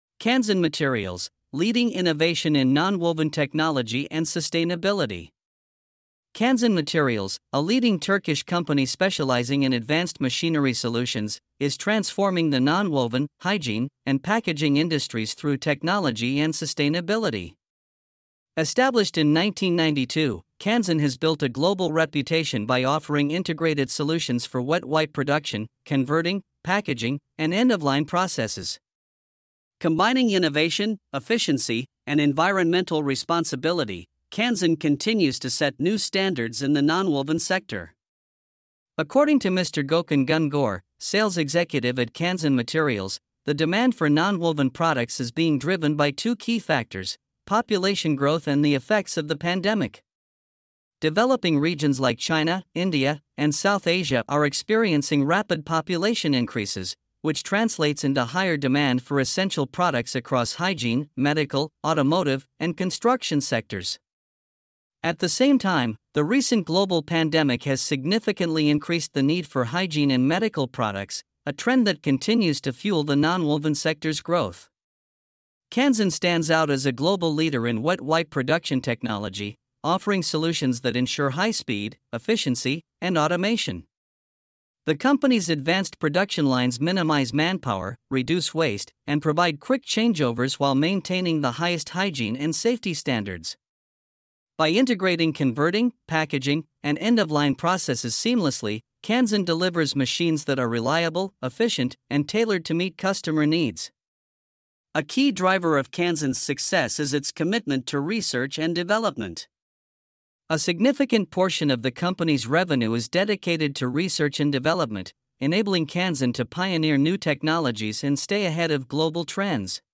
InterviewMiddle East and AfricaTürkiyeNonwovens